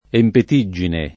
empetiggine [ empet &JJ ine ]